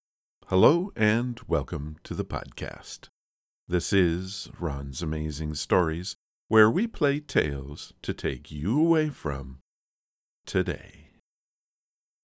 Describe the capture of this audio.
The second file is with Just the De-Ess plug-in (Settings: Threshold -25.0 dB Frequencies 2500 to 9559 - Everything else was left default)